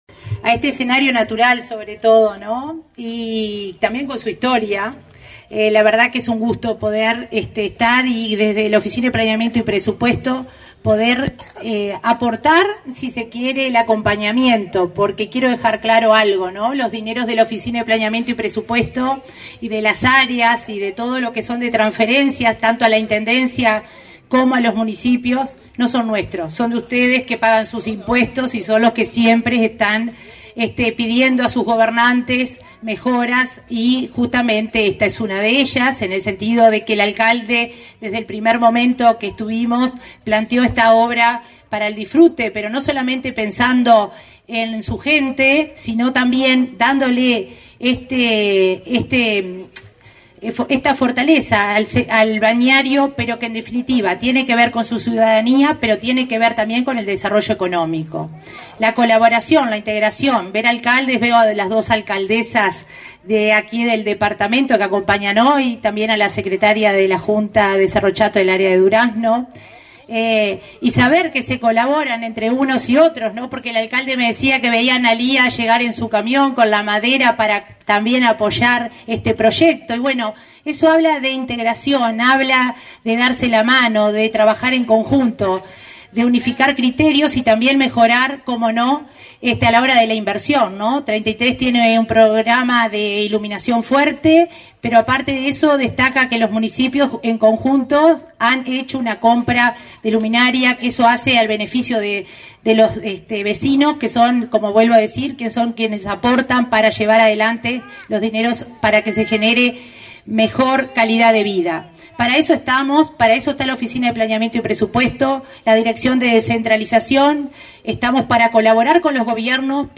Palabras de la coordinadora del Área de Descentralización y Cohesión de la OPP, María de Lima
Palabras de la coordinadora del Área de Descentralización y Cohesión de la OPP, María de Lima 08/12/2022 Compartir Facebook X Copiar enlace WhatsApp LinkedIn La Oficina de Planeamiento y Presupuesto (OPP) inauguró, este 8 de diciembre, obras de rearmado de la piscina de Salto de Agua, en Cerro Chato. La coordinadora de OPP participó del evento.